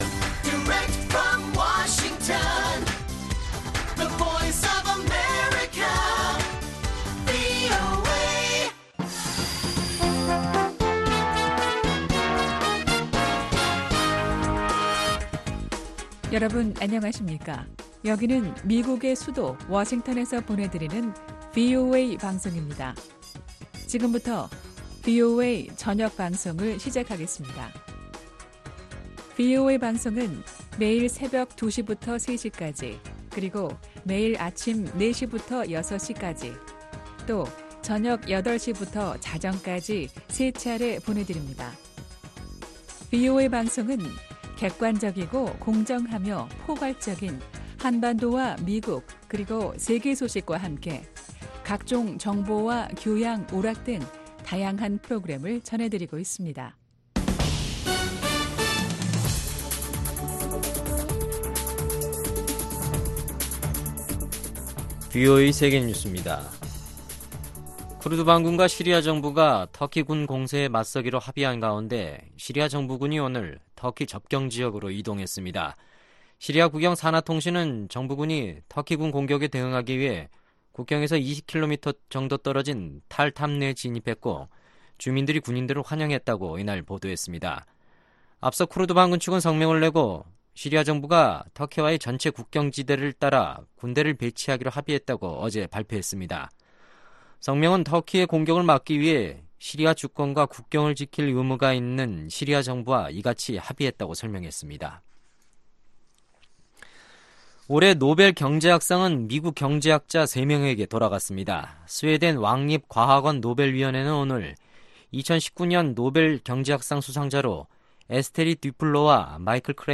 VOA 한국어 간판 뉴스 프로그램 '뉴스 투데이', 2019년 10월 14일 1부 방송입니다. 한국과 북한이 15일 평양에서 국제축구경기를 치릅니다.